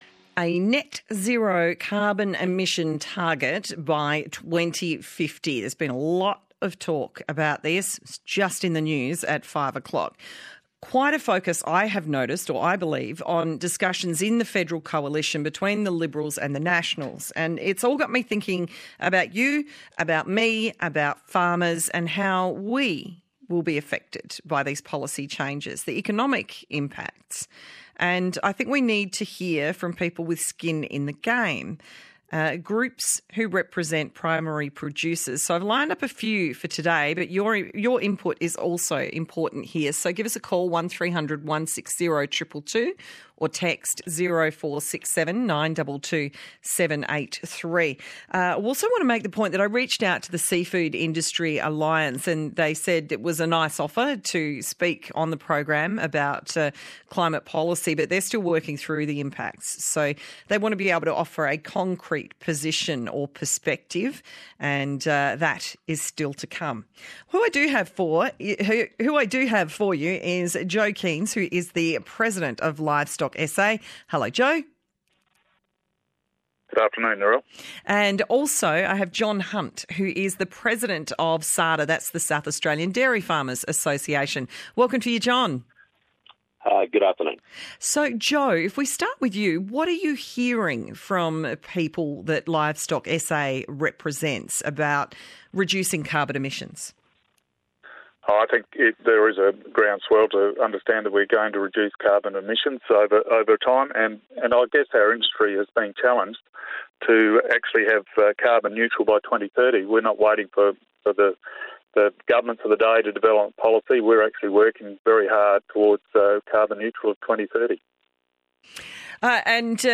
ABC Regional Drive - NetZero By 2050 Discussion with Livestock SA…